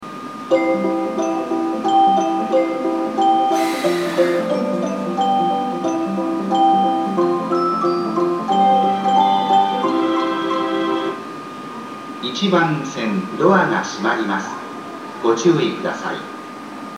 木更津駅　Kisarazu Station ◆スピーカー：BOSE
内房線・久留里線共に、ATOS未導入線区ですが、駅自動放送はATOS型放送が使用されています。
1番線発車メロディー